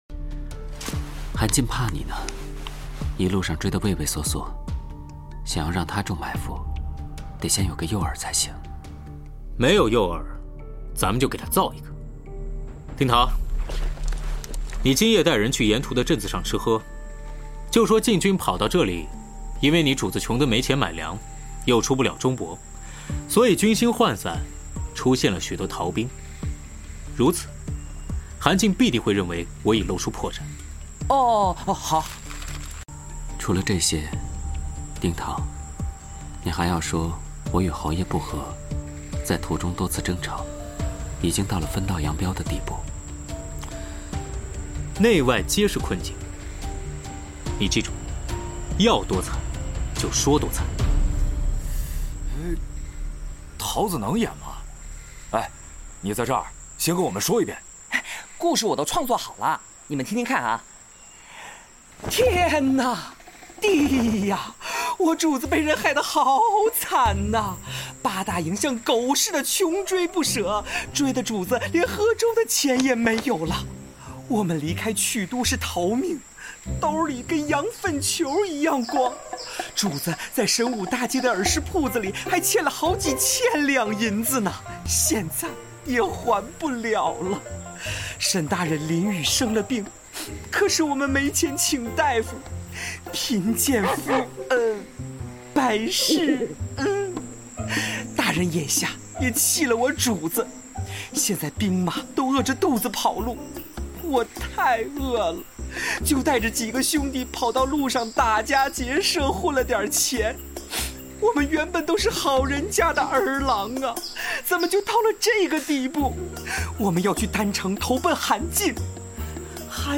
QJJ audio drama S2E9 sound effects free download